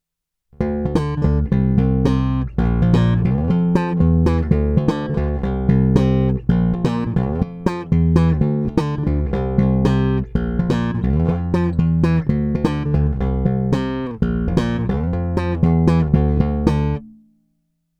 Slap se staženou tónovou clonou na ½